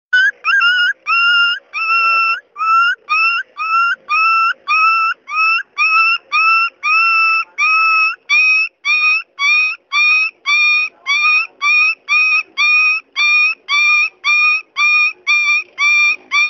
Pets And Animals Ringtones